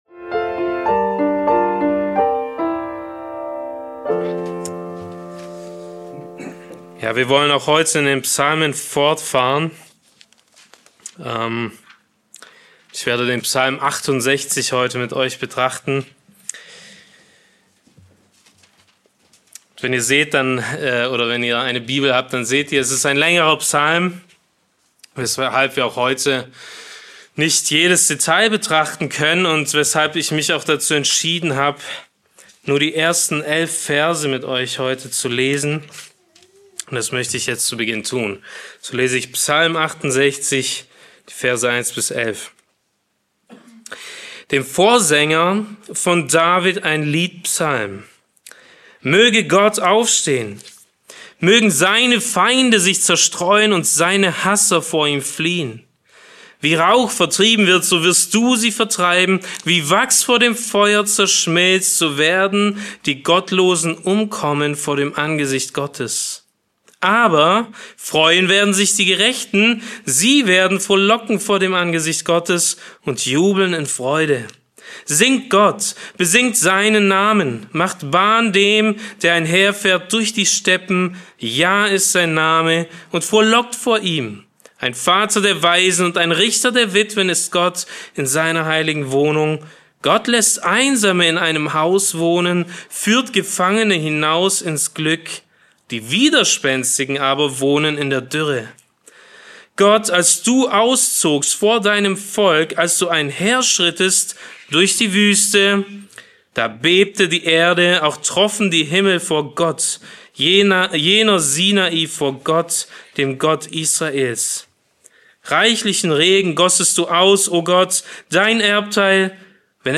Die Predigt behandelt anhand von Psalm 68, wie Gläubige ein siegreiches Leben führen können.